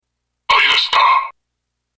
Chaque bouchon a une voix enregistrée qui lui est associée, cliquez sur le nom du bouchon pour l'écouter.